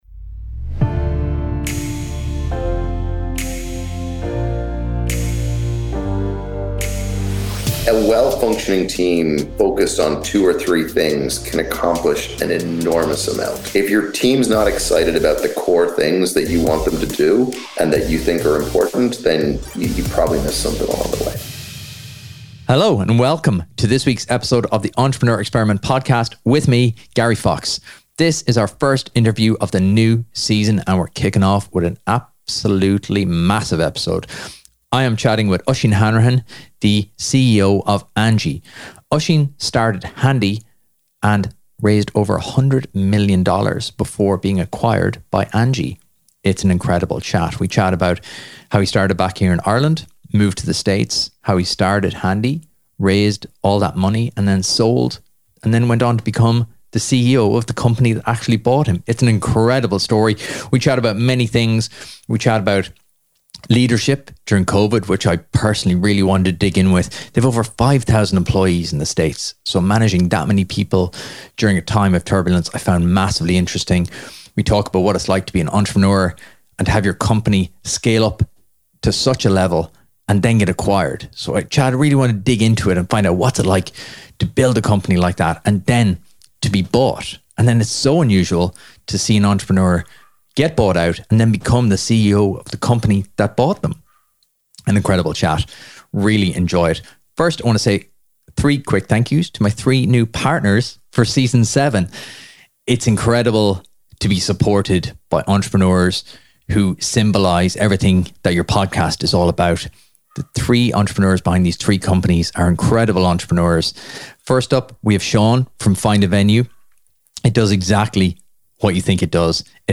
In a wide ranging chat, we discuss leadership during Covid, what it feels like to sell your company and how he remains productive as a CEO of a public company.